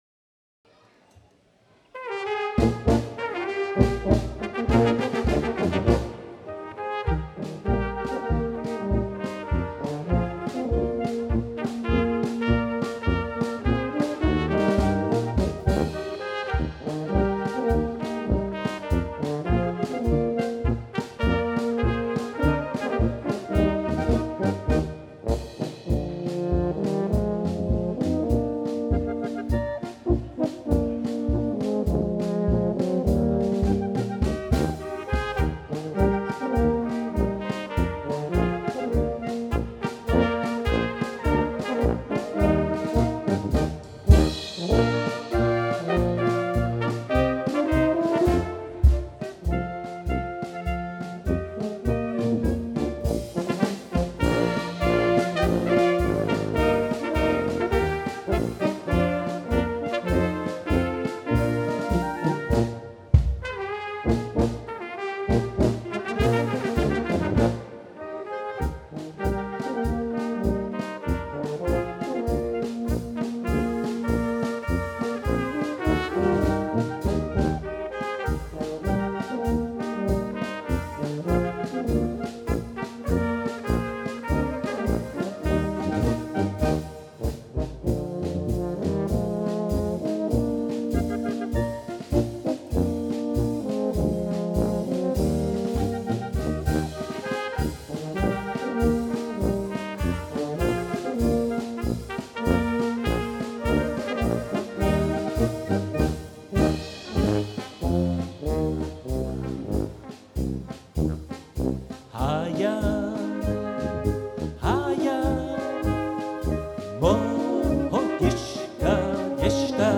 Böhmische Blasmusik aus dem Havelland